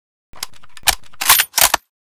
unjam.ogg